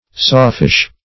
Sawfish \Saw"fish`\, n. (Zool.)